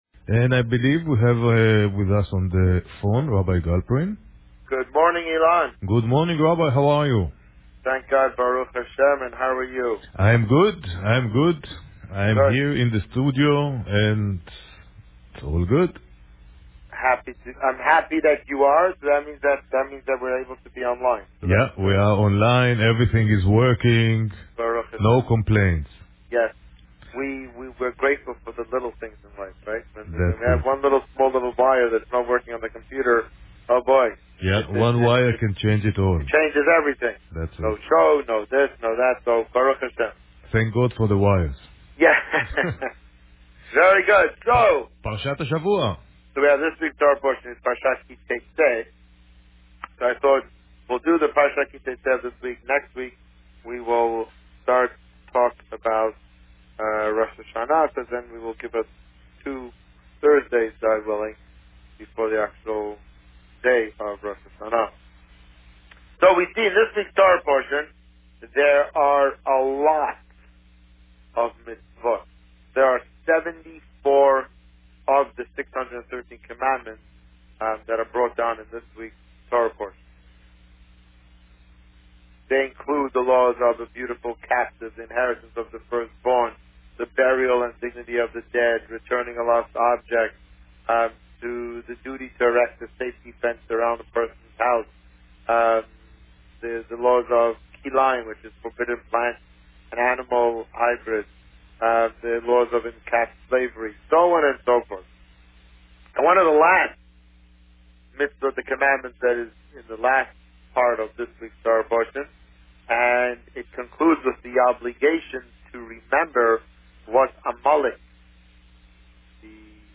This week, the Rabbi spoke about Parsha Ki Teitzei. Listen to the interview here.